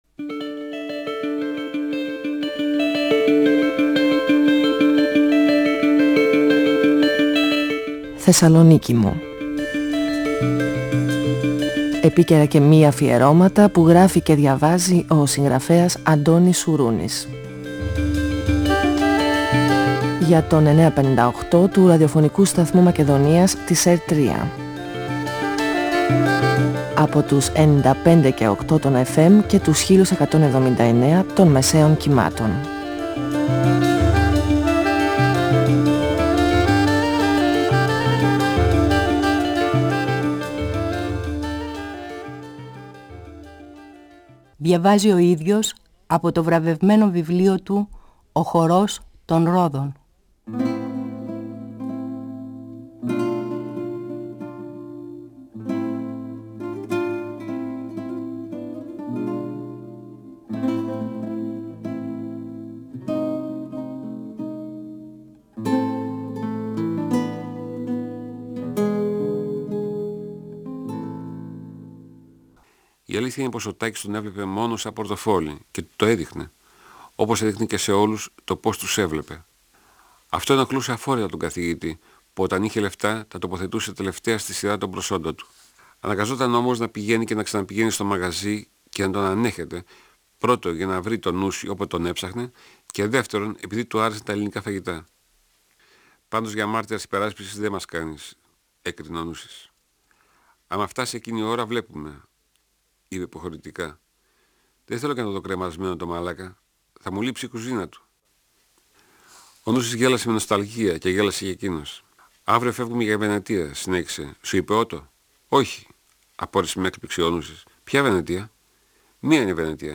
Ο συγγραφέας Αντώνης Σουρούνης (1942-2016) διαβάζει από το βιβλίο του «Ο χορός των ρόδων», εκδ. Καστανιώτη, 1994. Ο Καθηγητής προγραμματίζει ταξίδι για τη Βενετία. Ο Νούσης δεν τον ακολουθεί γιατί θέλει να βοηθήσει τον Τάκη που βρίσκεται στη φυλακή.